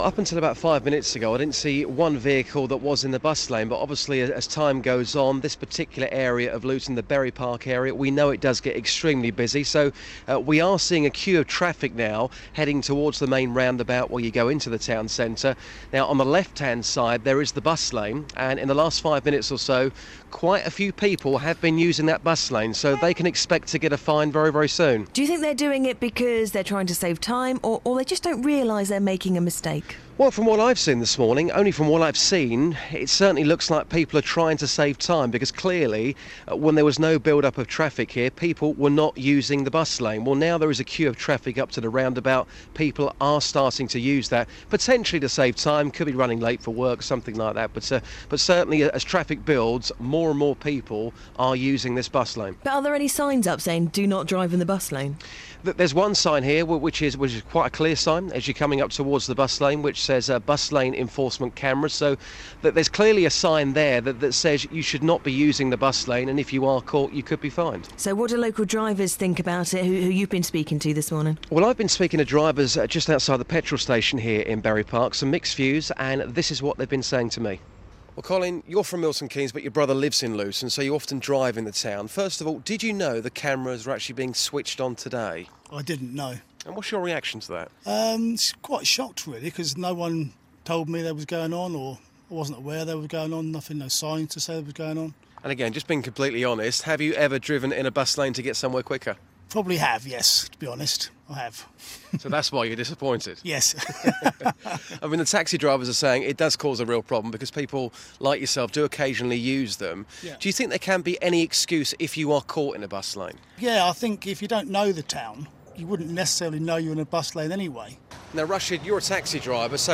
went out on the streets of Luton...